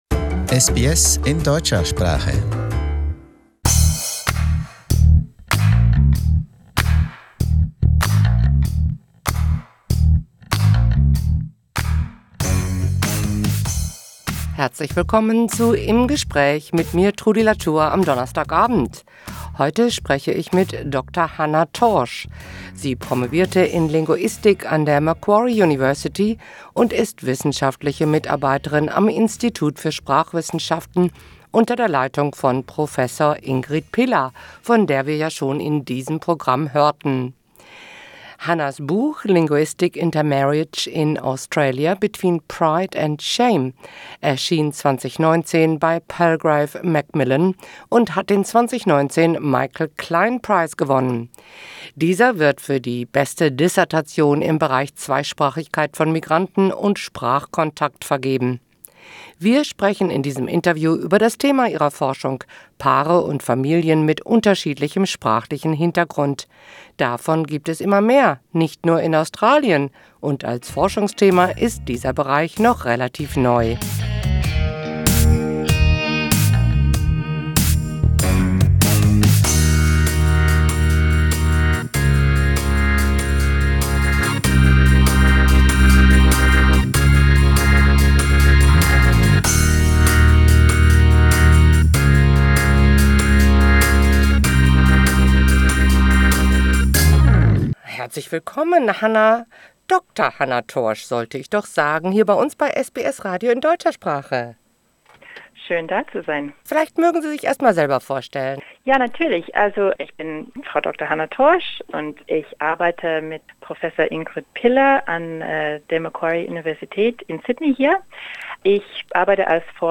Im Gespräch: Familien mit mehreren Herkunftssprachen